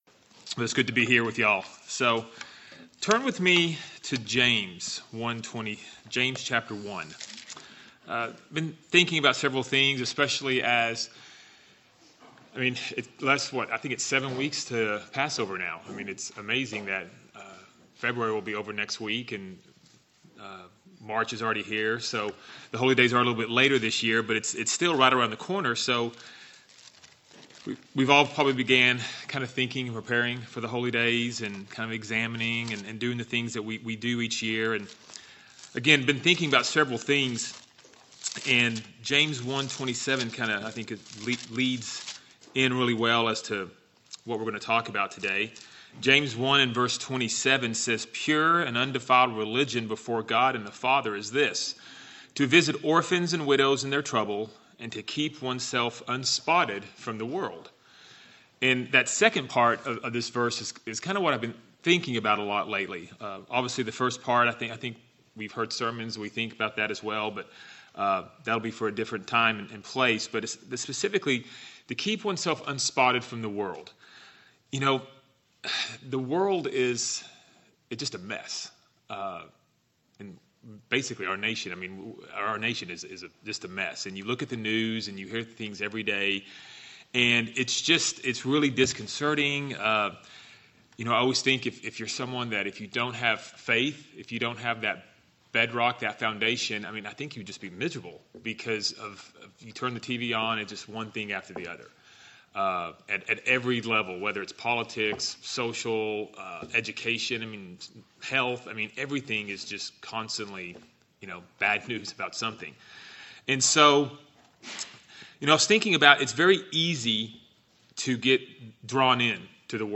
Sermons
Given in Lawton, OK